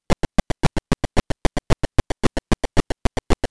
Game Sounds:
Multiple people wlaking
Multiple-People-Walking.wav